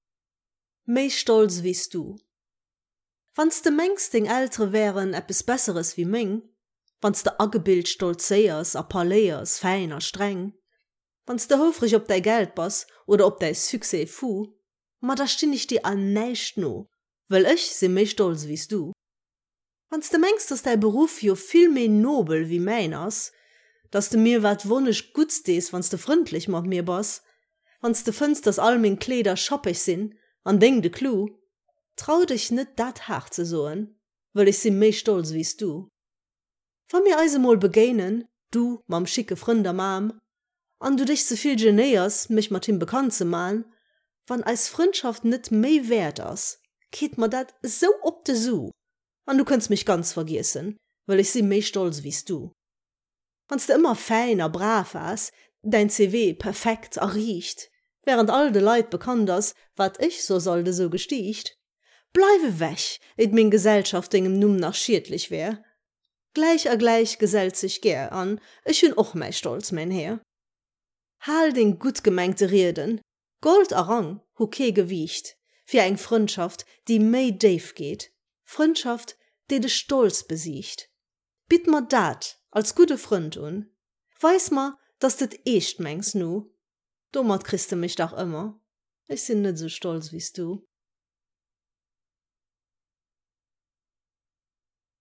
geschwate Versioun.